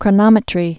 (krə-nŏmĭ-trē)